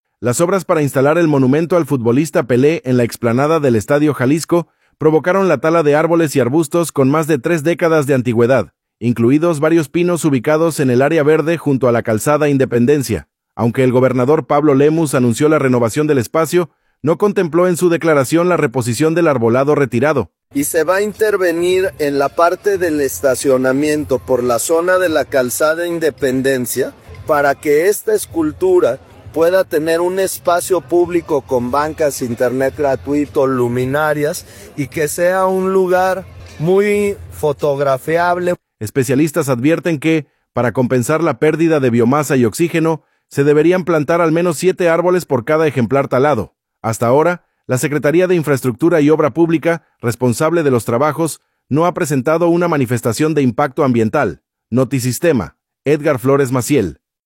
audio Las obras para instalar el monumento al futbolista Pelé en la explanada del Estadio Jalisco provocaron la tala de árboles y arbustos con más de tres décadas de antigüedad, incluidos varios pinos ubicados en el área verde junto a la Calzada Independencia. Aunque el gobernador Pablo Lemus anunció la renovación del espacio, no contempló en su declaración la reposición del arbolado retirado: